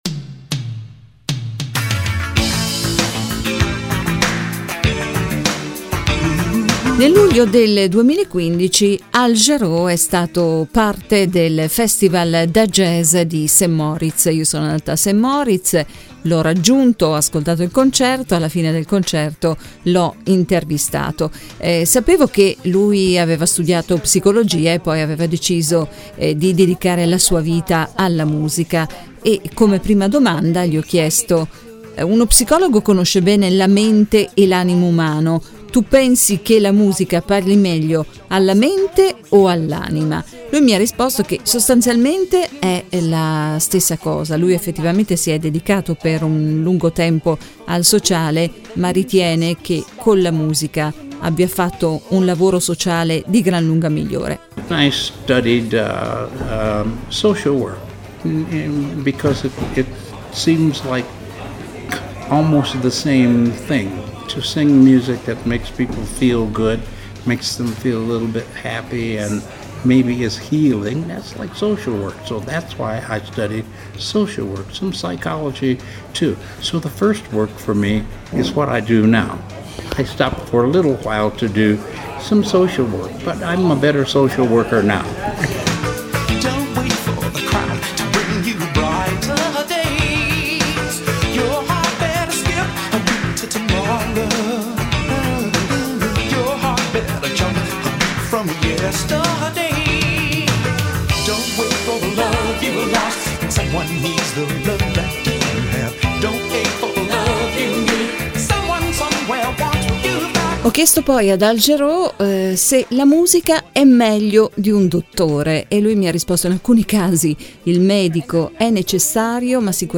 Intervista emozionante ad Al Jarreau, in concerto a St. Moritz.